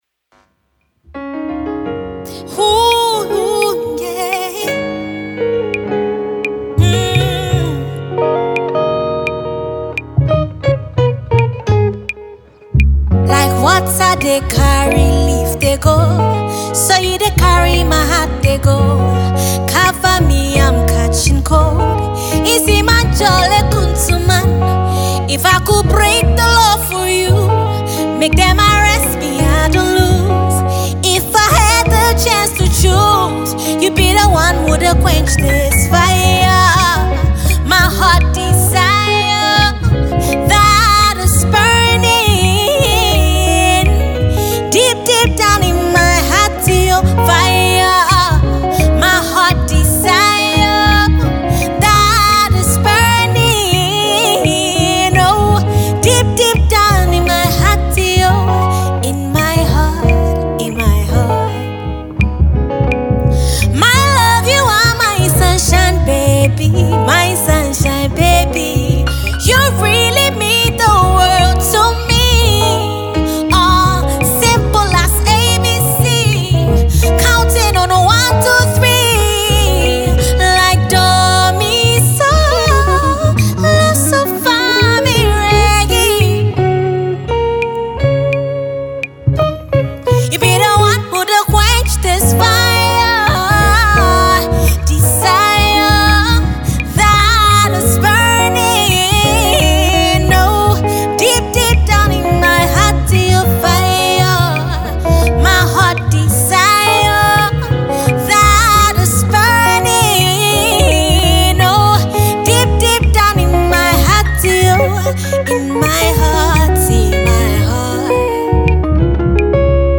a Ghanaian songstress
acoustic production